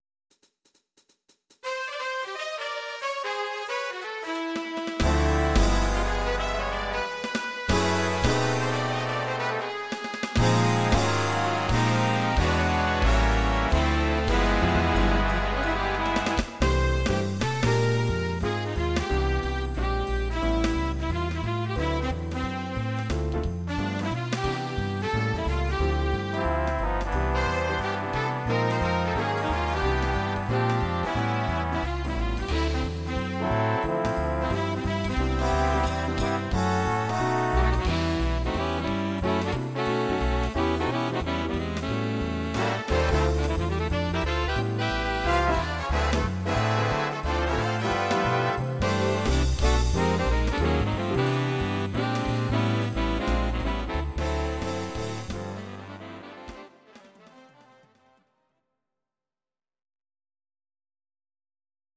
Please note: no vocals and no karaoke included.
Your-Mix: Jazz/Big Band (731)